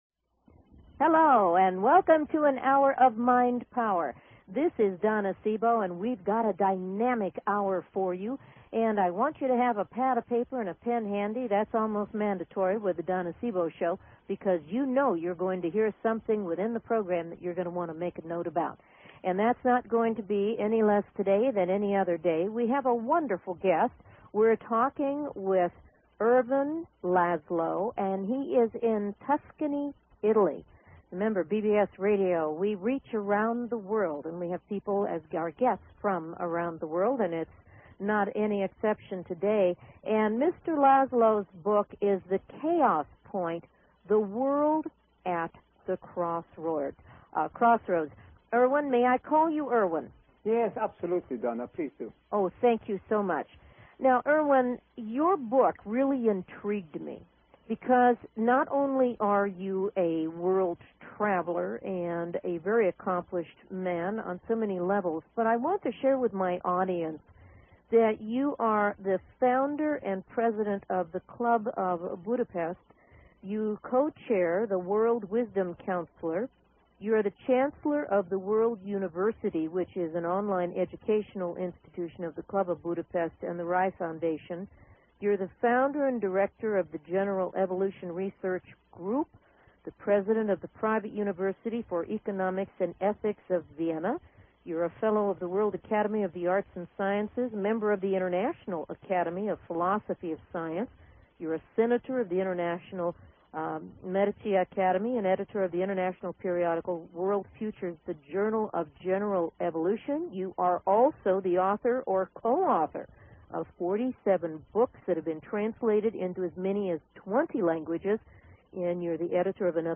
Talk Show Episode
"The Chaos Point", by E. Lazlo. Join me as I interview this fascinating many who has helped to form a global organization dedicated to world peace and a healthy world economy. We will be talking with him in Tuscany, Italy. During the second half of the program you are welcome to call in and I will be happy to 'Light Up Your Life with a Little Bit of Insight'.